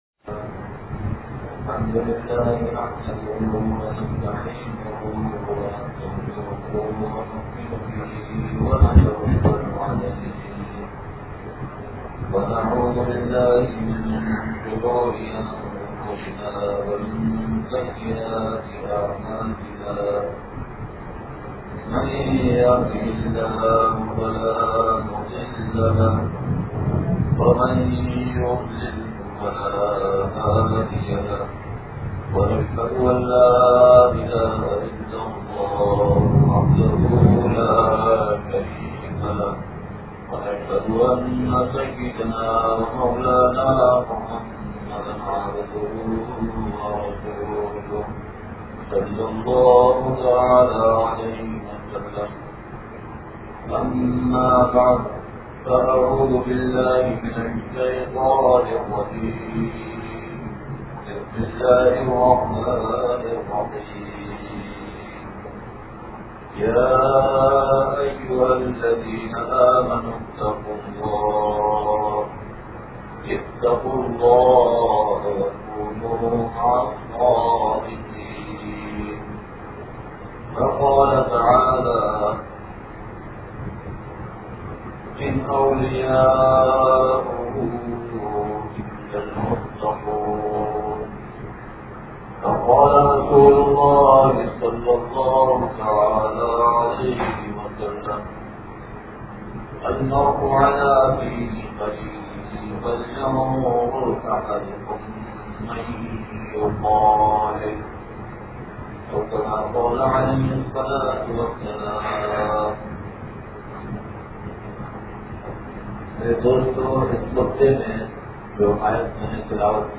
بیان بعد نماز مغرب جامع عالمہ مسجد غریب آباد نوابشاہ سندھ